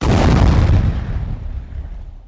1 channel
EXPLODE2.mp3